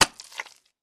Звуки грязи
Шум грязевых брызг о стену